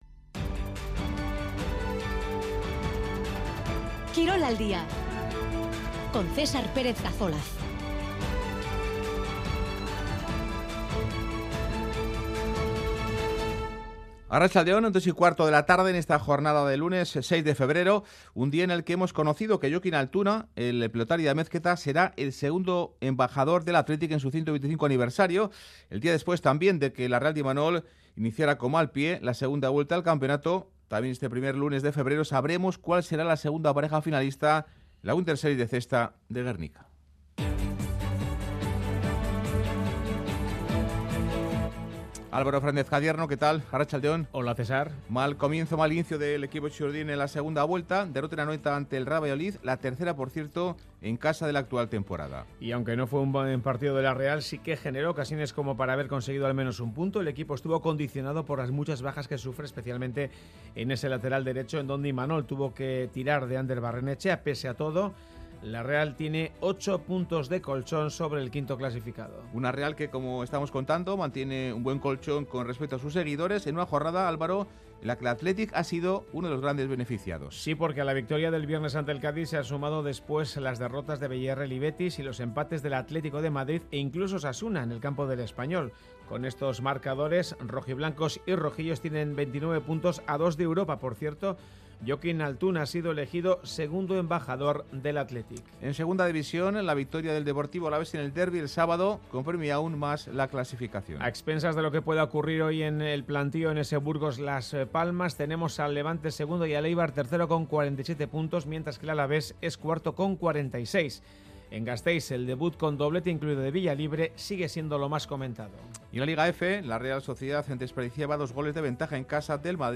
Informativo de actualidad deportiva